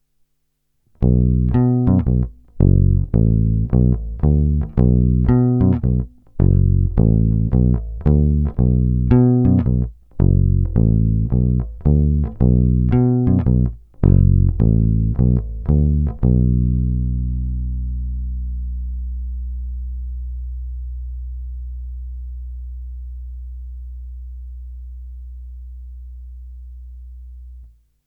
Zvuk asi mnohým sedět nebude, má výrazný kontrabasový charakter, kratší sustain, ale jinak je zamilováníhodný.
Není-li řečeno jinak, následující nahrávky jsou vyvedeny rovnou do zvukovky a kromě normalizace ponechány bez zásahů.
Hra nad snímačem – zacloněno